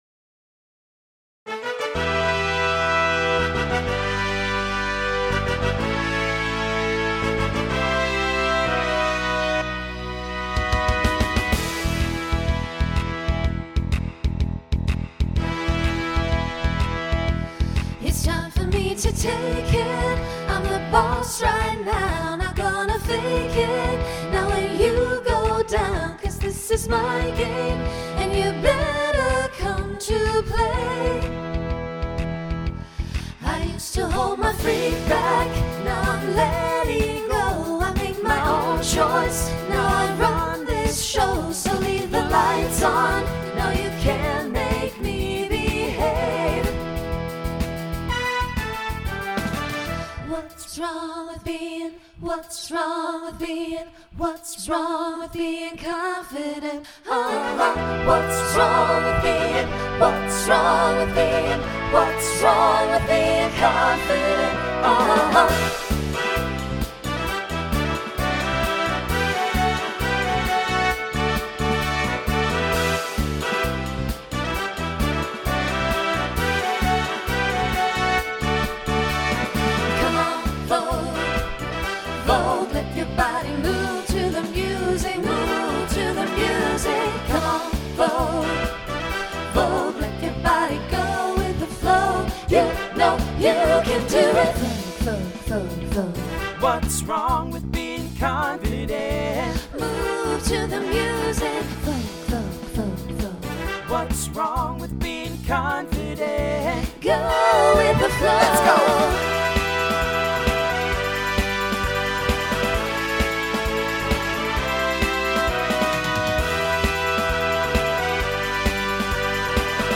New SSA voicing for 2020
SSA Instrumental combo Genre Pop/Dance Decade 1990s
Mid-tempo